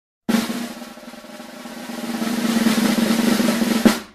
drumroll.ogg